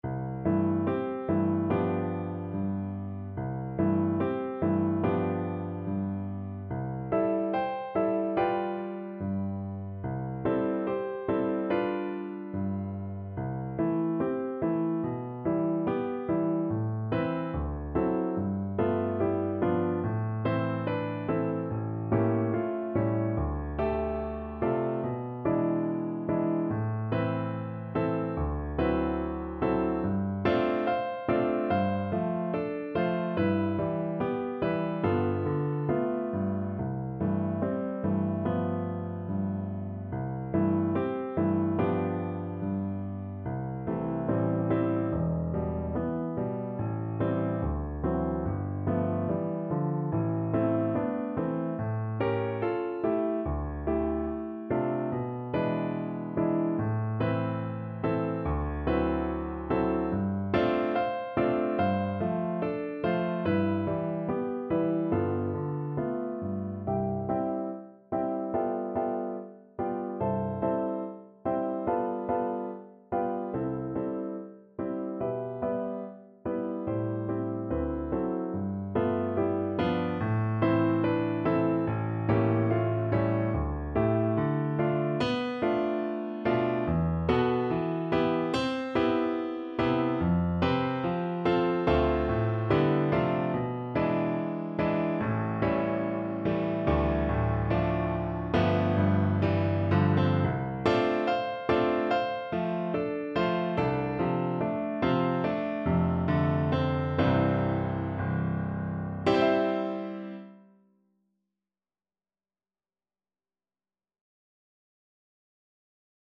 ~ = 72 In moderate time
4/4 (View more 4/4 Music)
Classical (View more Classical Flute Music)